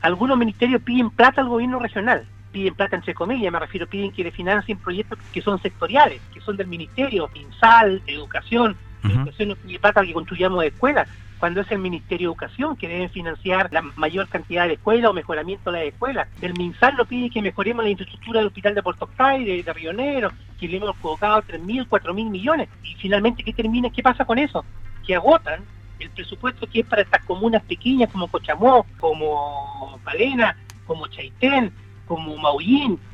En conversación con Radio Sago, Casanova puso hincapié en resolver los problemas que existen con aquellas empresas que se adjudican obras con recursos regionales, para luego abandonarlas.